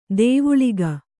♪ dēvuḷiga